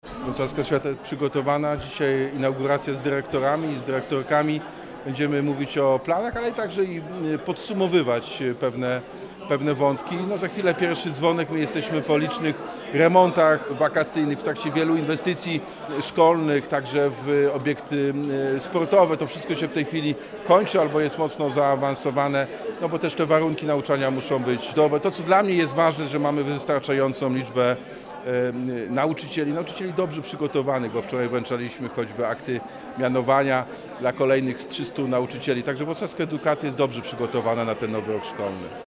O kulisach mówi Prezydent Wrocławia Jacek Sutryk.